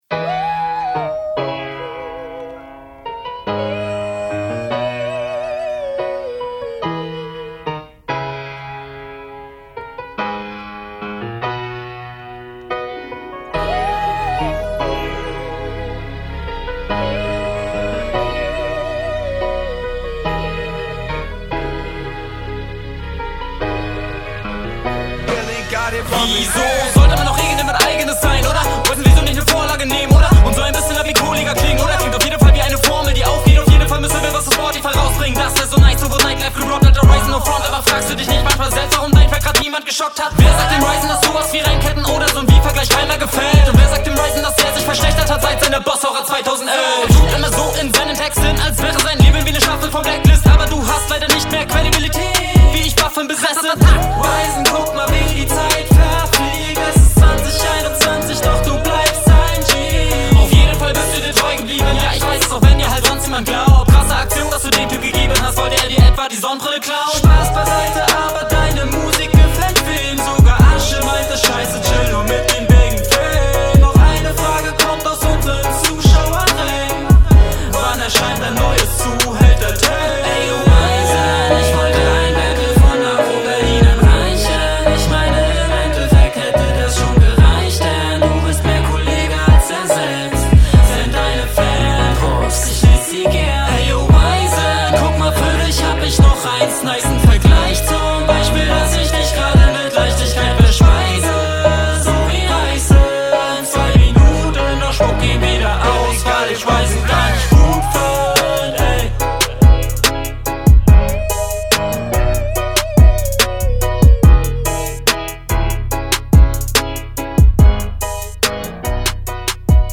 Einstieg kommt super fresh.